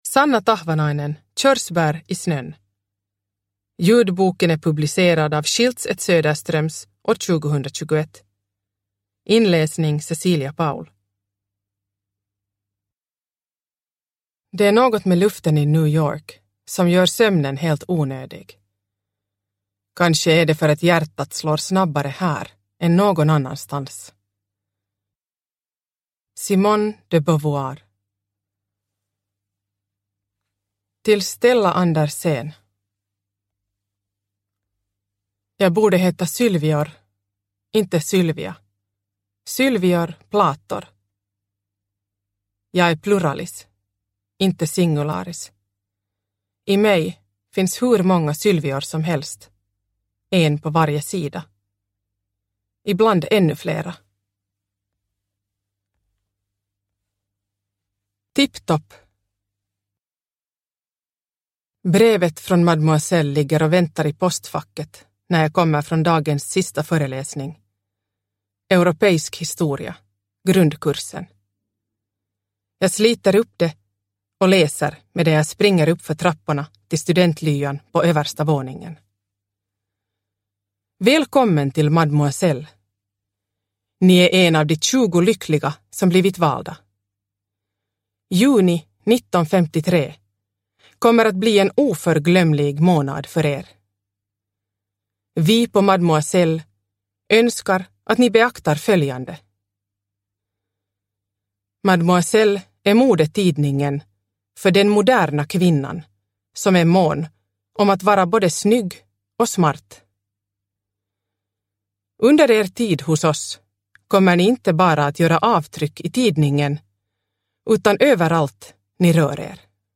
Körsbär i snön – Ljudbok – Laddas ner